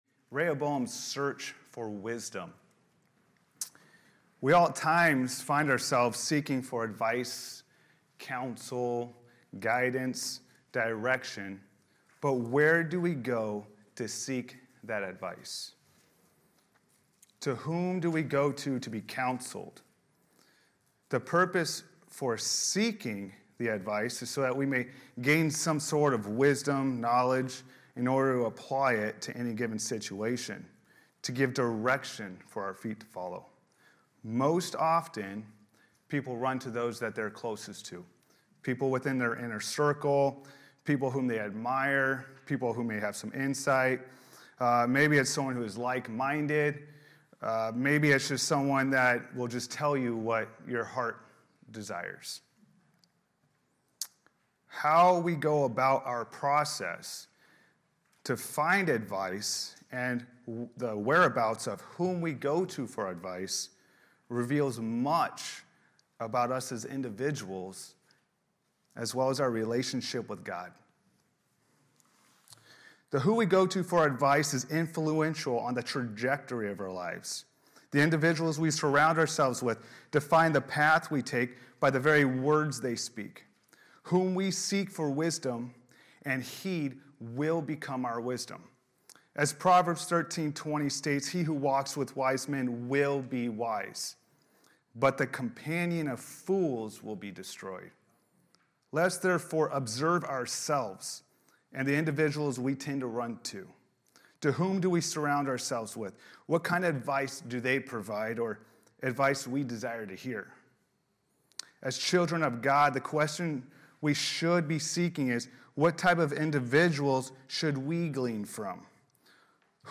Passage: 1 Kings 12:1-15 Service Type: Sunday Morning Service Related « Timothy